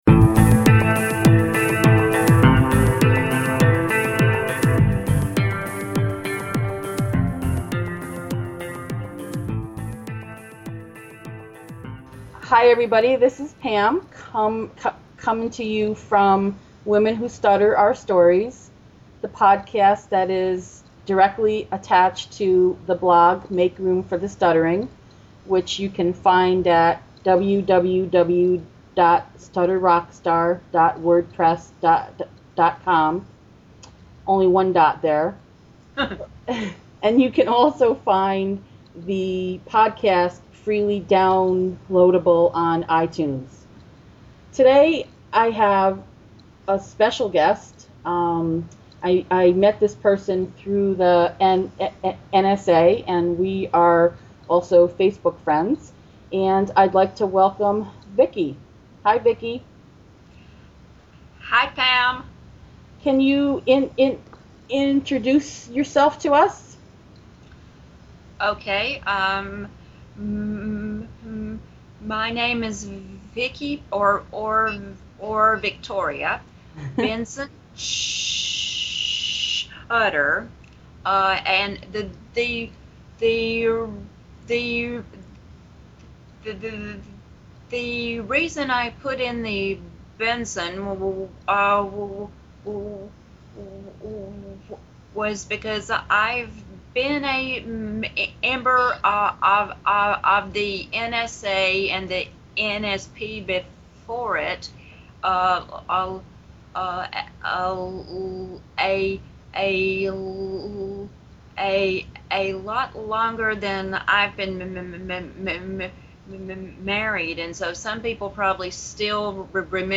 Listen in to an engaging, funny and inspirational conversation.